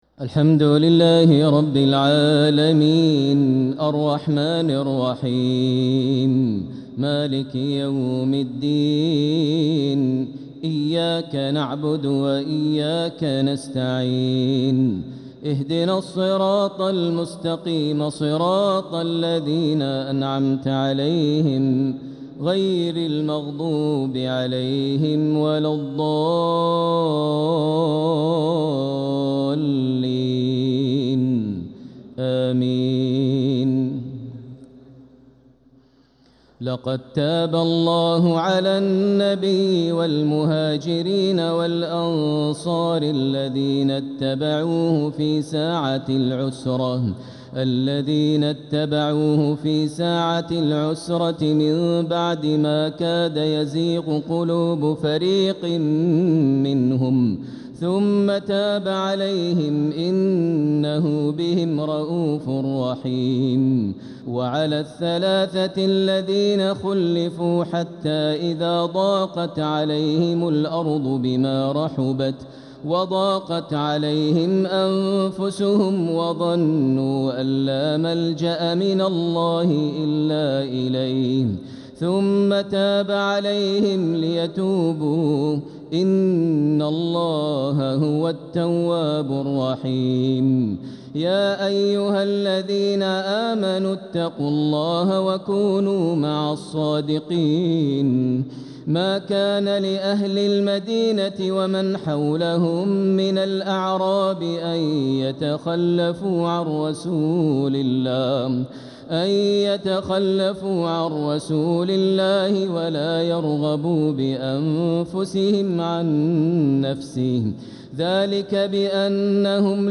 تراويح ليلة 14 رمضان 1446هـ من سورتي التوبة (117_129) و يونس (1-25) | Taraweeh 14th niqht Surat At-Tawba and Yunus 1446H > تراويح الحرم المكي عام 1446 🕋 > التراويح - تلاوات الحرمين